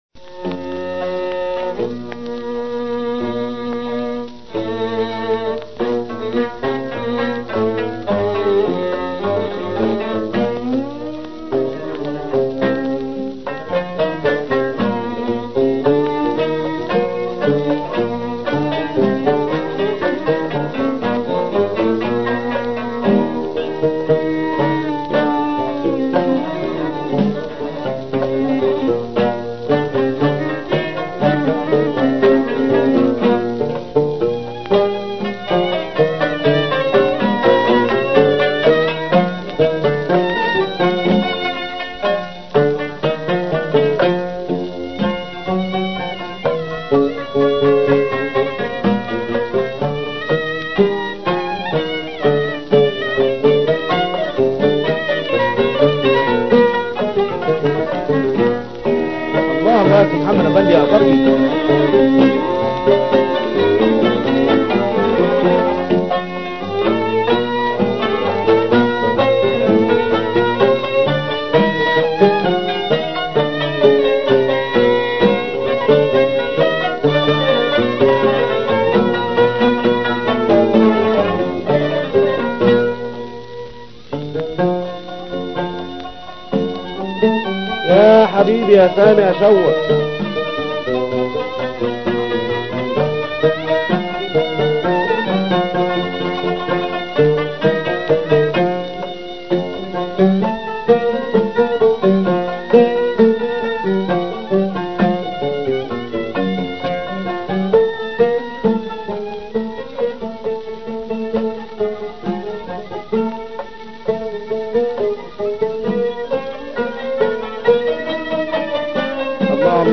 • Instrumental (no vocals)
• Melodically rich, exploring the maqām in a clear, structured way
• Rhythmically steady, often with a long repeating cycle